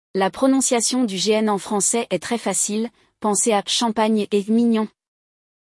Pronúncia!
Mas, apesar de serem escritas quase da mesma forma, as duas palavras têm o som do GN um pouco diferentes: em português, o G e o N são pronunciados separadamente, enquanto, em francês, formam o som de “nhã” que comentamos antes.